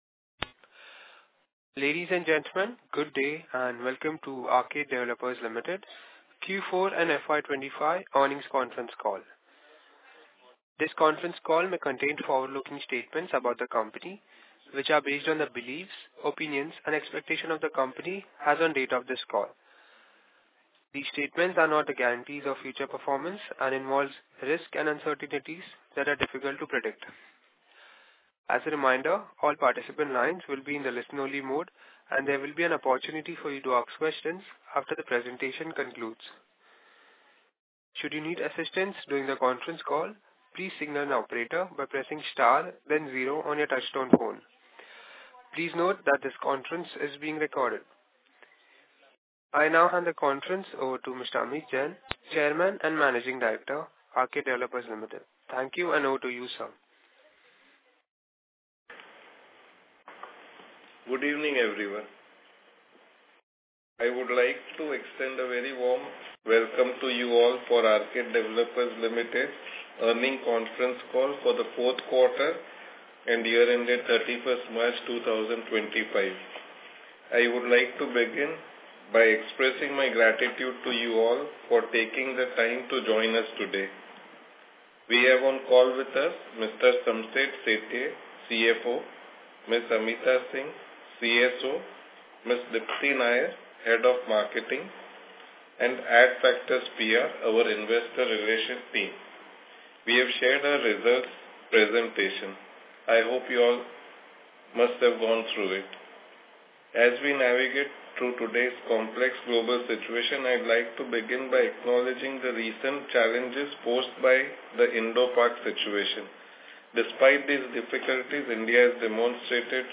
Audio-Recording-for-Earnings-Conference-Call-Q4FY24-25.mp3